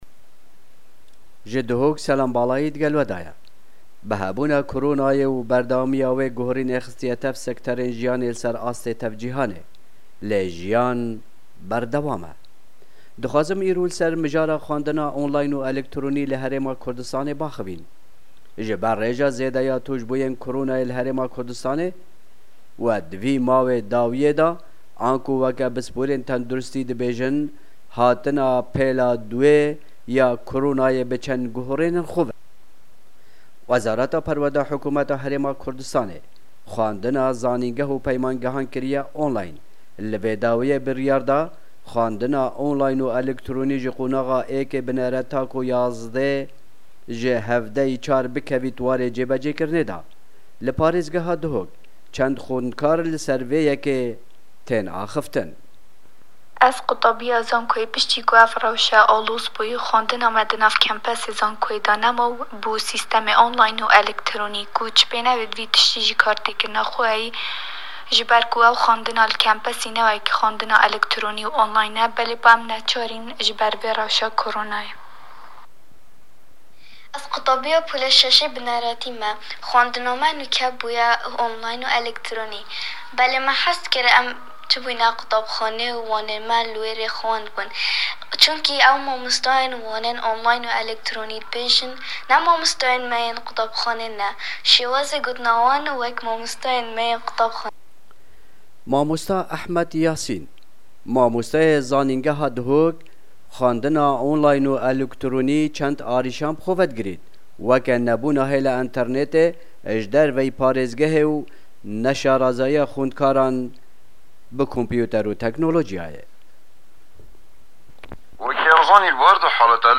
Li parêzgeha Duhokê çend xwenedkar li ser vê mijarê axivîn û got ku ew bêhtir ji xwandina xwandingehaan û mamostayên xwe dikin, lê ji ber koronayê ew dê online bixwînin.